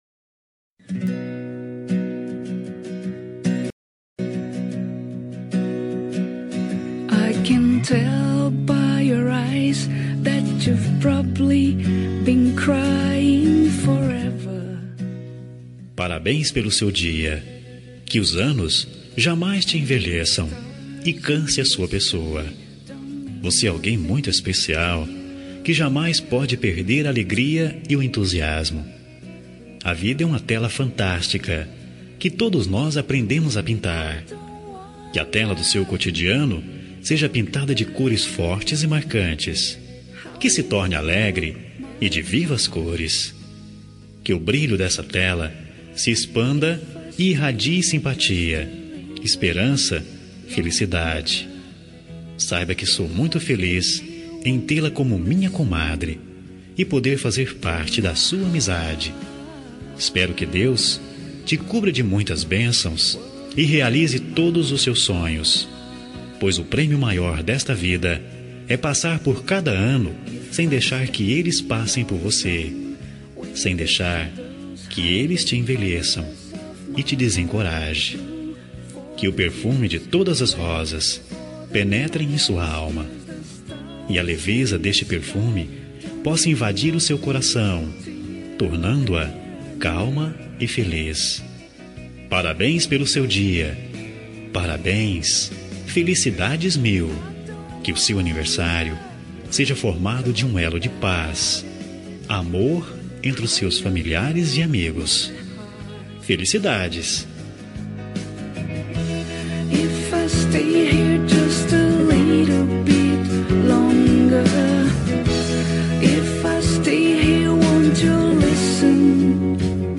Aniversário de Comadre – Voz Masculina – Cód: 202147